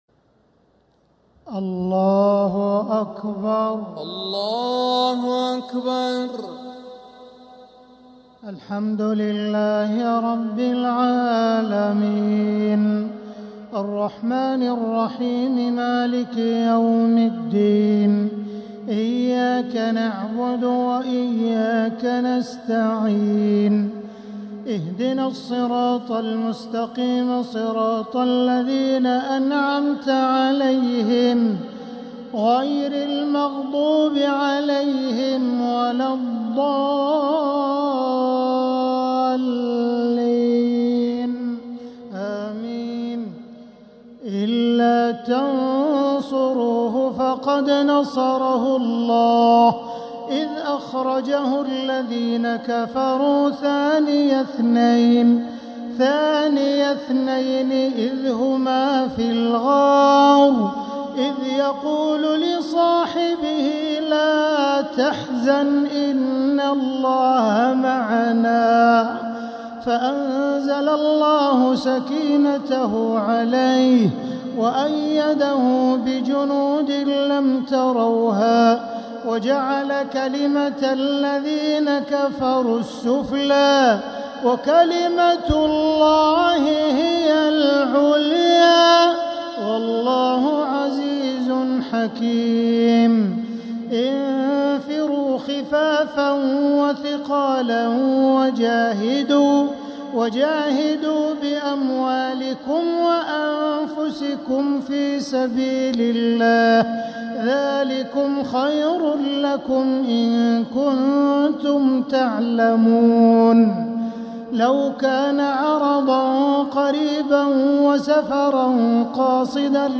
تراويح ليلة 13 رمضان 1447هـ من سورة التوبة (40-60) | taraweeh 13th niqht Surah At-Tawba 1447H > تراويح الحرم المكي عام 1447 🕋 > التراويح - تلاوات الحرمين